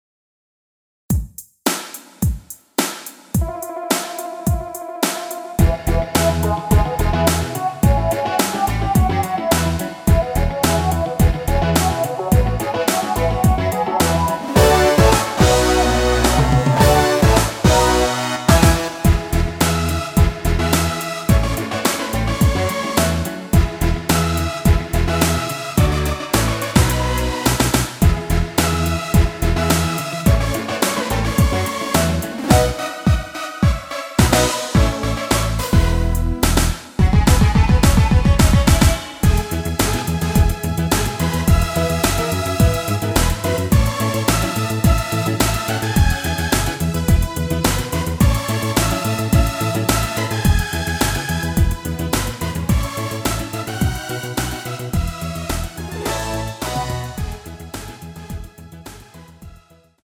멜로디 MR입니다.
원키 멜로디 포함된 MR입니다.
앞부분30초, 뒷부분30초씩 편집해서 올려 드리고 있습니다.
중간에 음이 끈어지고 다시 나오는 이유는